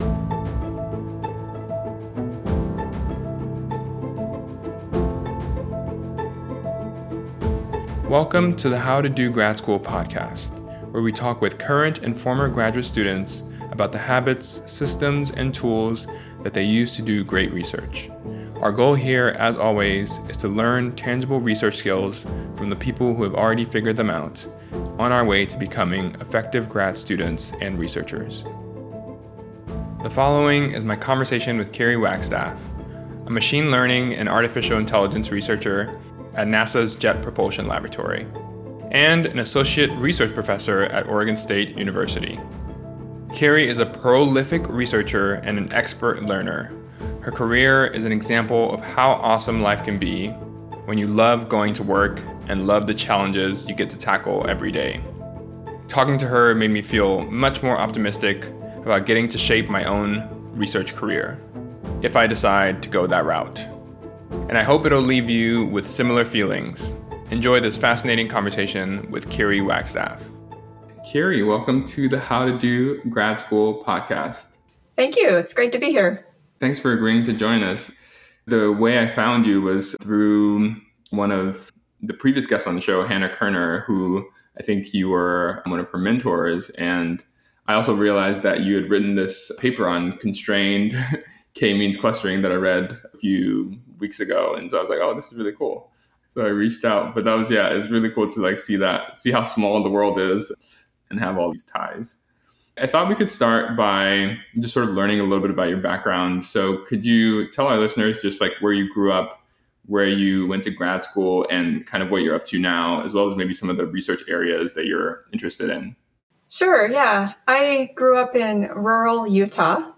Interview with the How to Do Grad School podcast: Why We Learn, How to Write, & Fostering Collaboration (August 2021, mp3, 42 mins)